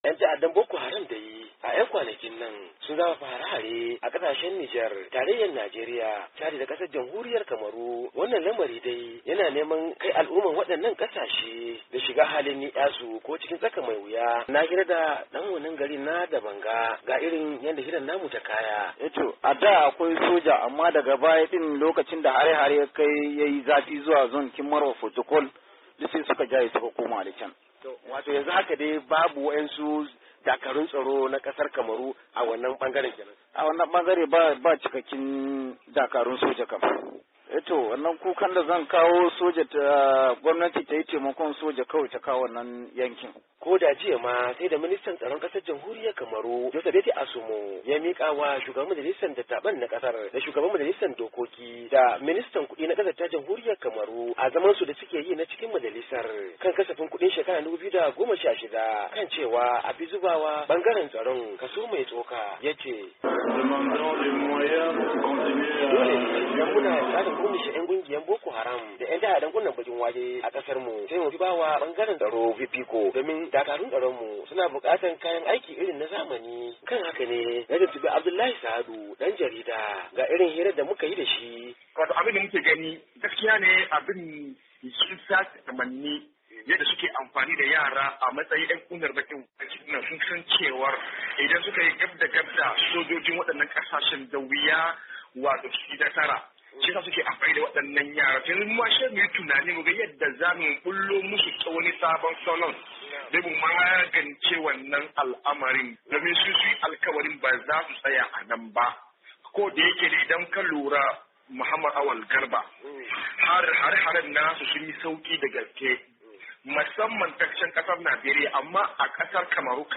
Ga cikakken rahoton nan a kasa